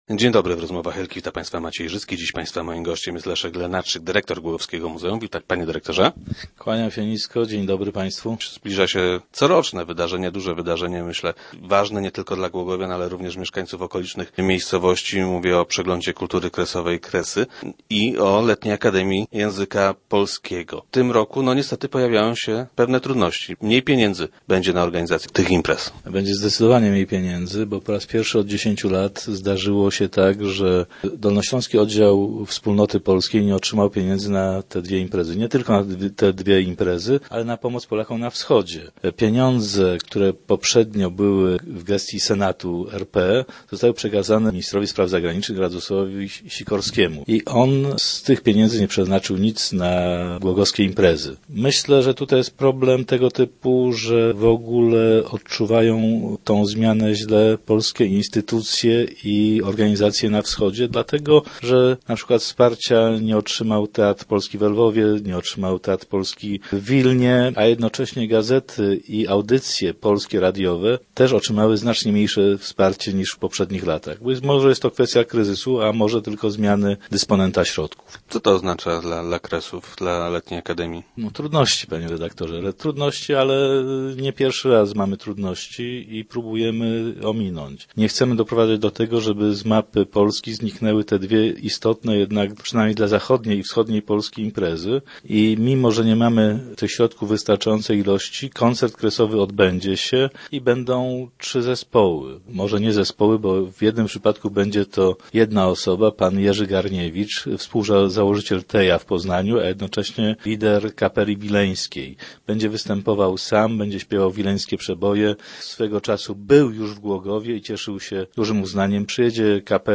który był gościem poniedziałkowych Rozmów Elki.